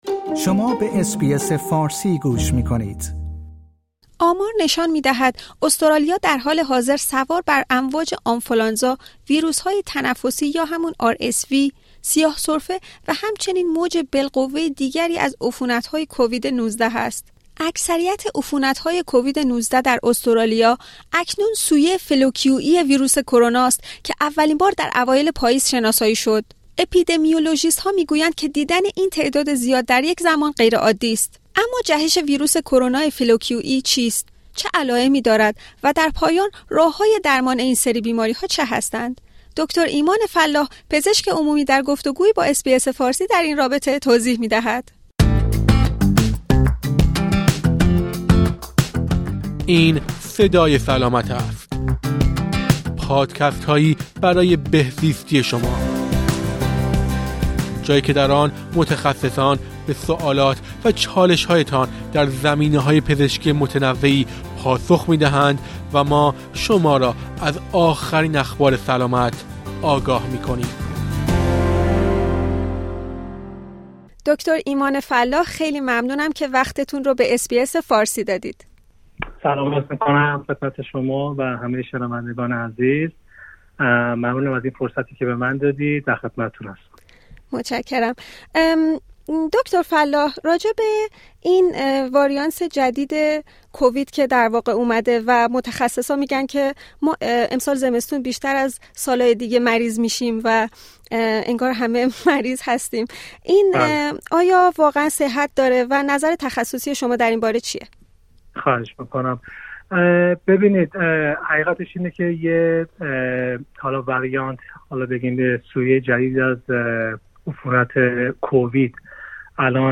پزشک عمومی در گفت‌وگویی با اس‌بی‌اس فارسی در این رابطه توضیح می‌دهد.